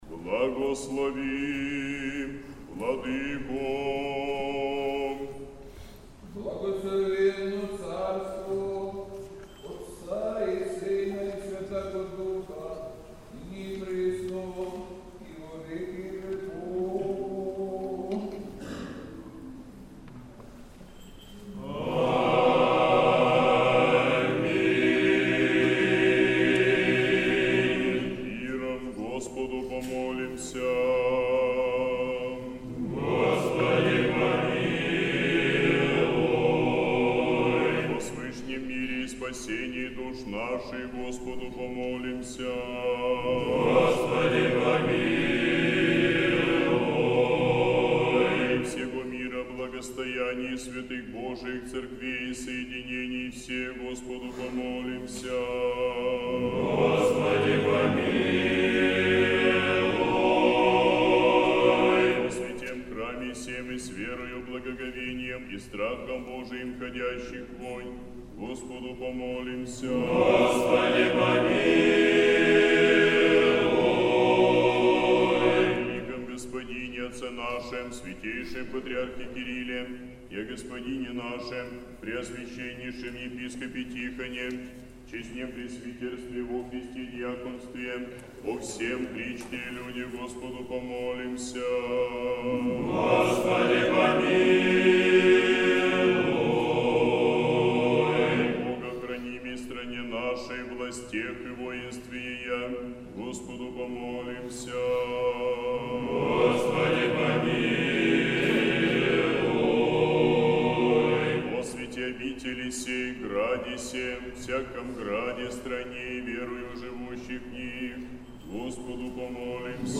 Божественная литургия в Сретенском монастыре на Покров Пресвятой Богородицы
Божественная литургия. Хор Сретенской духовной семинарии.